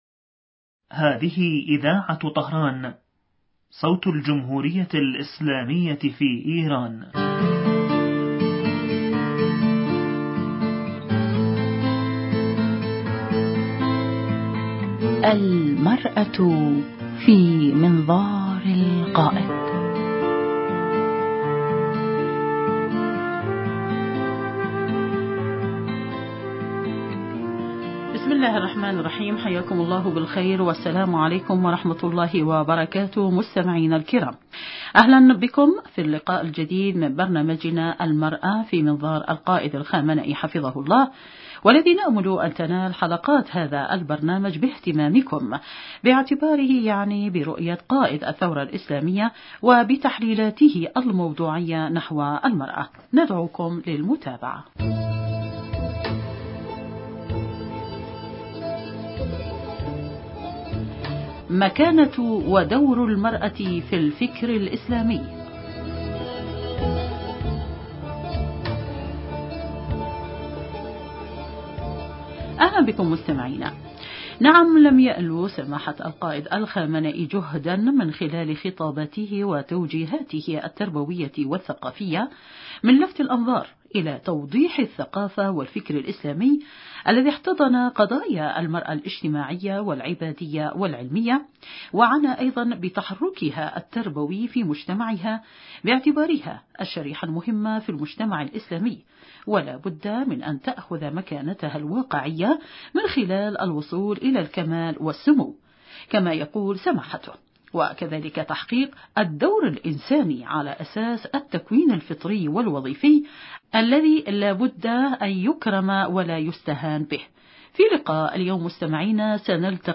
ضيفة البرنامج عبر الهاتف